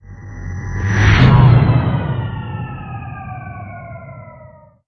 RomulanExitWarp.wav